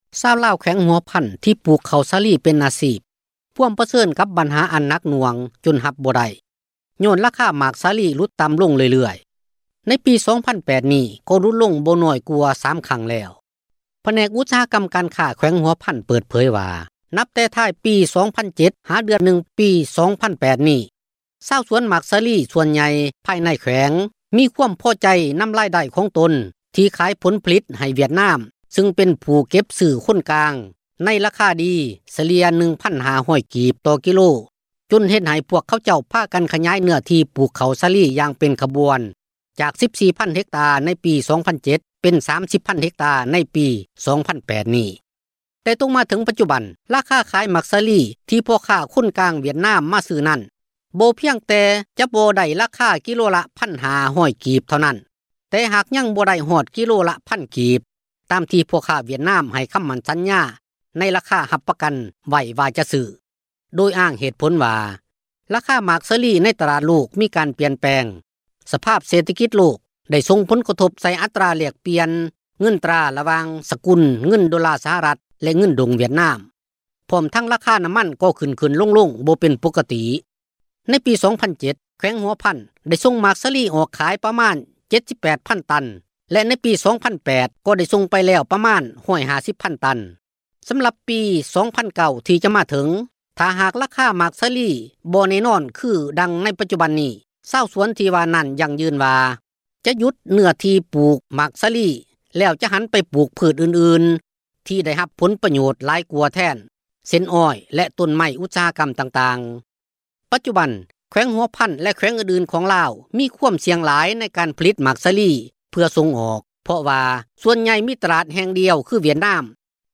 ຣາຍງານ